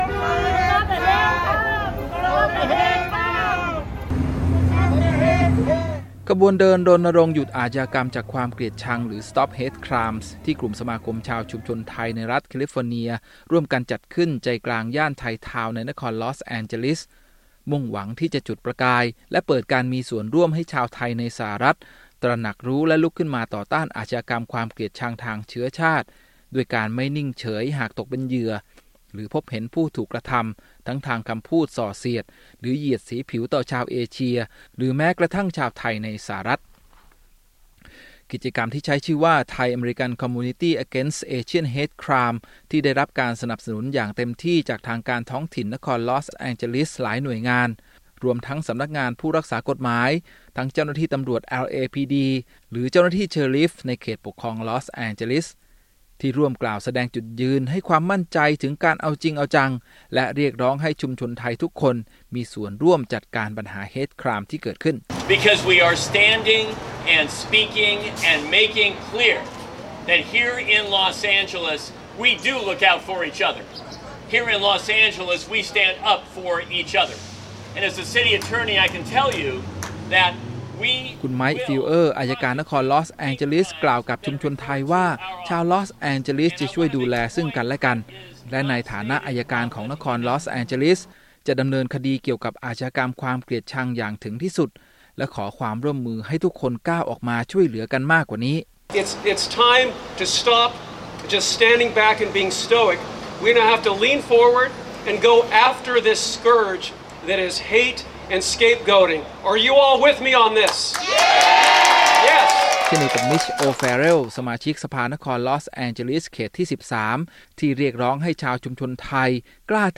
ขบวนเดินรณรงค์หยุดอาชญากรรมจากความเกลียดชัง หรือ Stop the Hate Crimes ที่กลุ่มสมาคมของชาวชุมชนไทยในรัฐแคลิฟอร์เนีย ร่วมกันจัดขึ้นใจกลางย่านไทยทาวน์ในนครลอส แอนเจลิส มุ่งหวังที่จุดประกายและเปิดการมีส่วนร่วมให้ชาวไทยในสหรัฐฯ ตระหนักรู้และลุกขึ้นมาต่อต้านอาชญากรรมความเกลียดชังทางเชื้อชาติด้วยการไม่นิ่งเฉยหากตกเป็นเหยื่อ หรือพบเห็นผู้ถูกกระทำทั้งทางคำพูดส่อเสียด หรือ เหยียดสีผิว ต่อชาวเอเชีย หรือชาวไทยในสหรัฐฯ